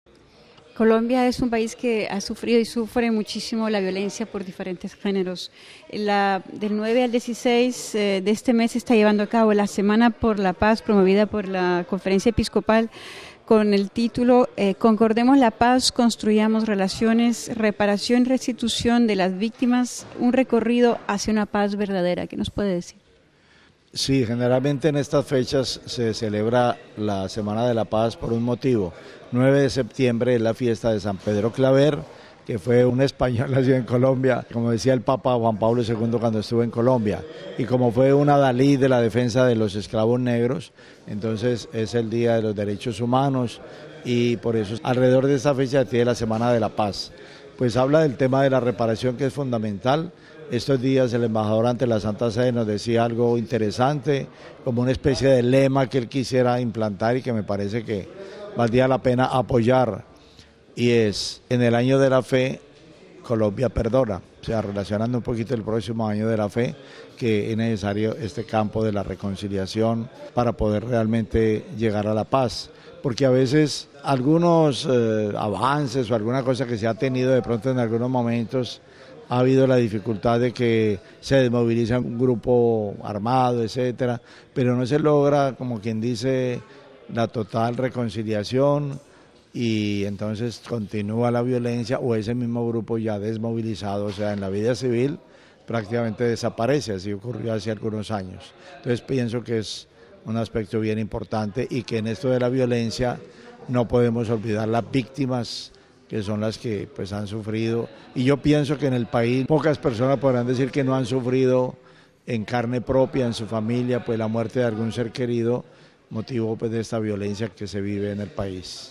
Para el arzobispo la próxima Semana por la Paz en Colombia afirma que es siempre necesaria para alcanzar la verdadera paz es necesaria la reconciliación y el perdón.